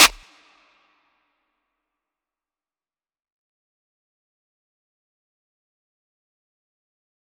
DMV3_Clap 2.wav